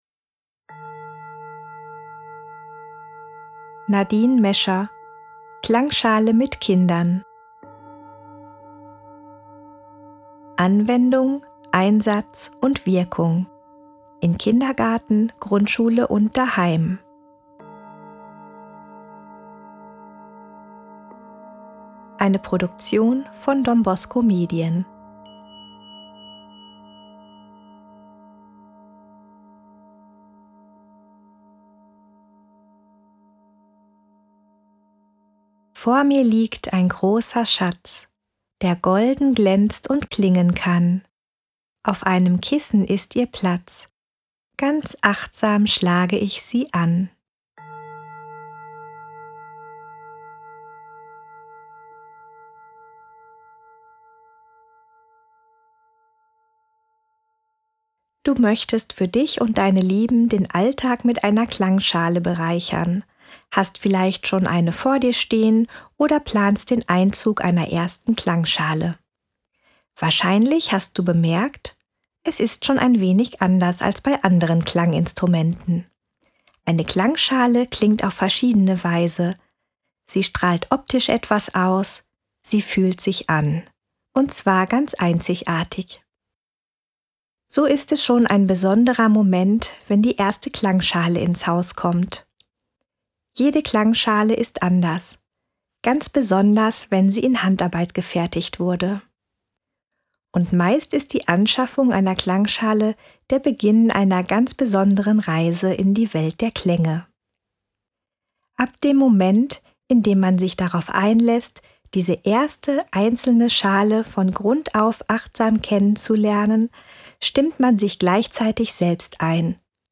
Audio/Hörbuch
Klangschale richtig anwenden - ein Hörbuch mit Klangschalenübungen zum Mitmachen & zur Inspiration für die Arbeit mit Kindern in Kita, Schule oder Gruppen